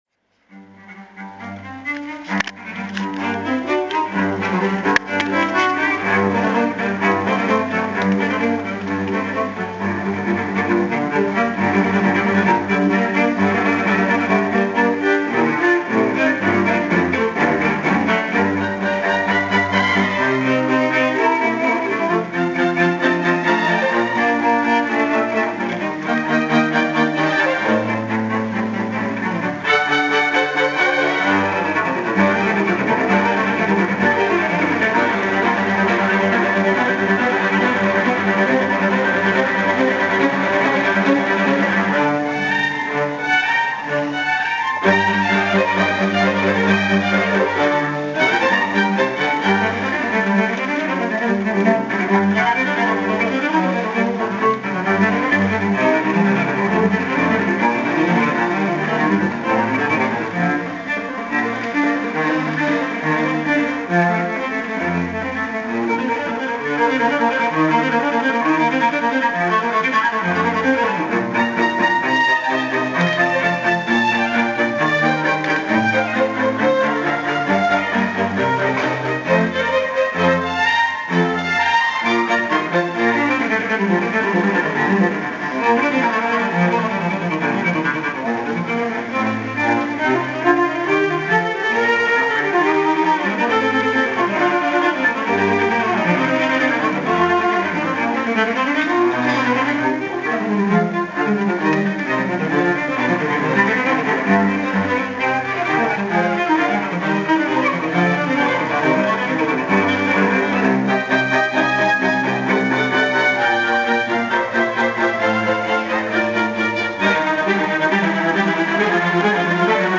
Brani tratti dal repertorio della grande musica classica e barocca (Beethoven, Haydn, Mozart, Debussy, Hindemith, Vivaldi, Mendelssohn) verranno eseguiti in alcuni dei luoghi più suggestivi di Barga: martedì 28 il chiostro del Conservatorio Santa Elisabetta, mercoledì 29 la centrale Piazza del Comune e venerdì 31 il Teatro dei Differenti.
Mercoledì 29 luglio – Barga (Lucca) – MUSICA NEI BORGHI – Concerto dell’Ensemble le Musiche – Piazza del Comune, ore 21.00 – (ingresso 5 €)